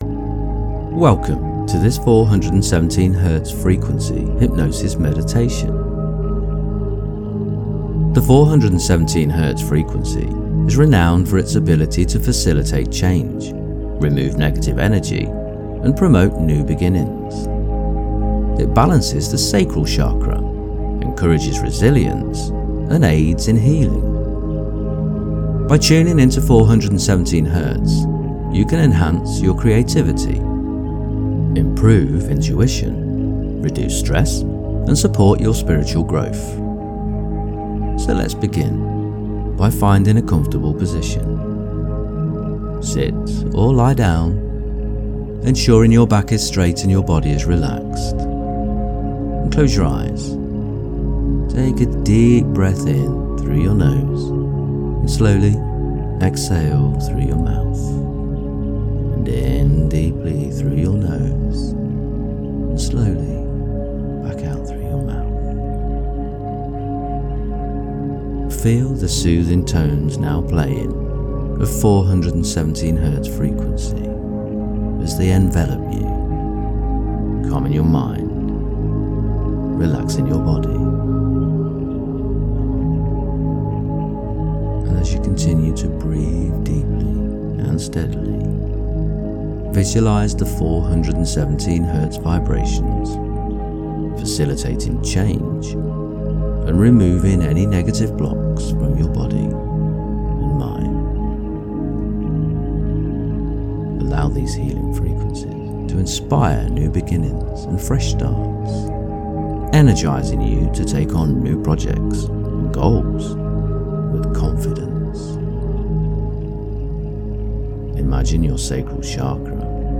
The transformative power of the 417 Hz frequency in this immersive meditation - Dynamic Daydreaming
417-meditation.mp3